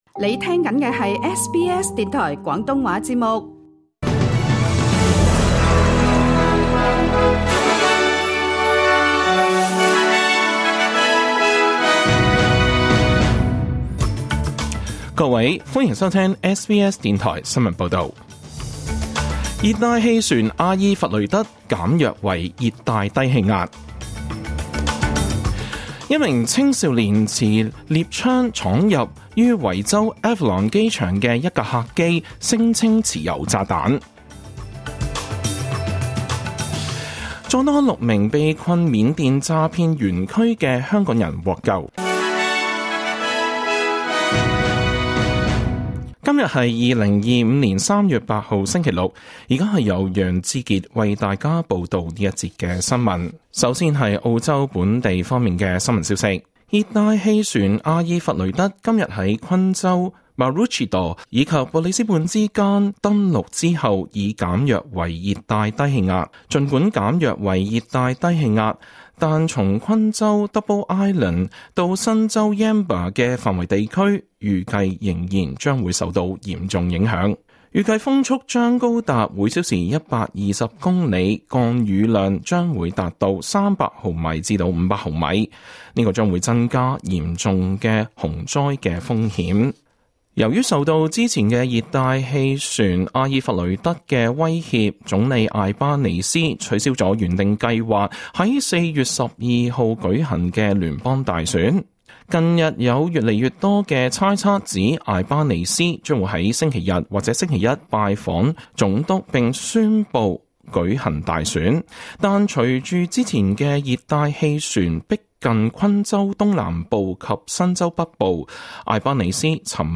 2025 年 3 月 8 日 SBS 廣東話節目詳盡早晨新聞報道。